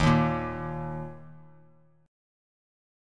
D#5 Chord
Listen to D#5 strummed